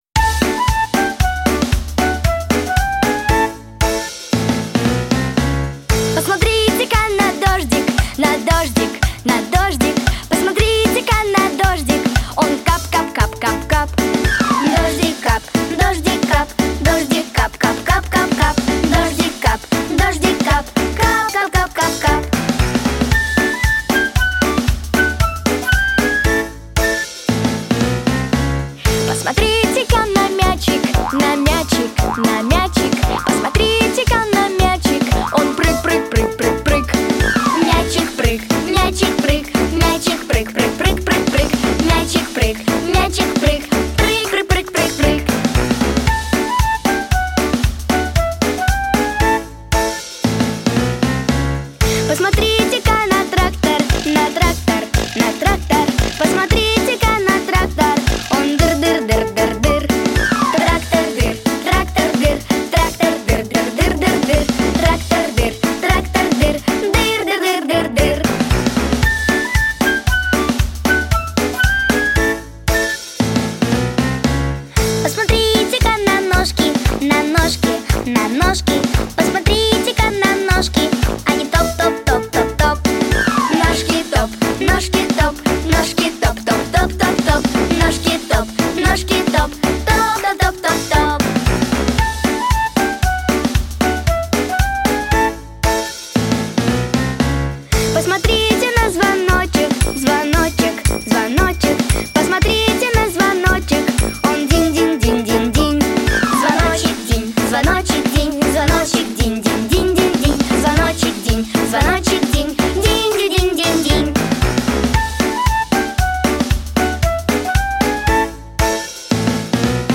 детские песни
Песни из мультфильмов